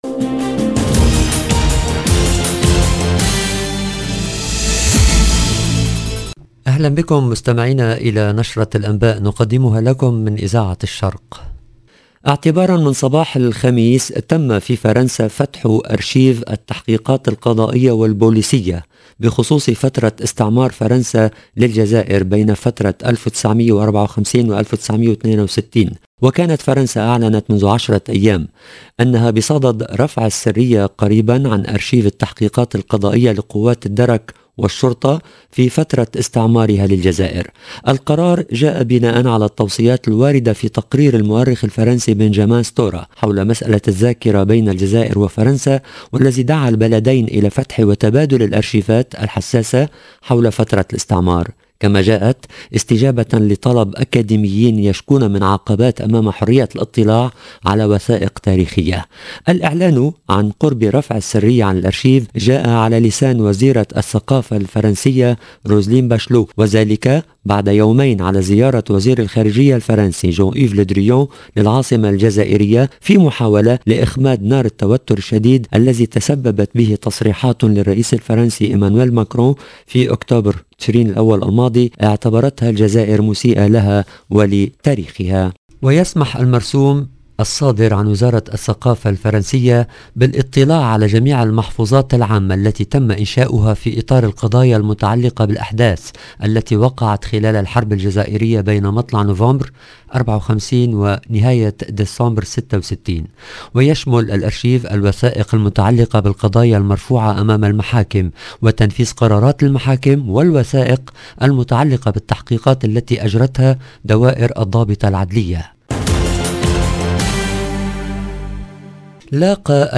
EDITION DU JOURNAL DU SOIR EN LANGUE ARABE DU 23/12/2021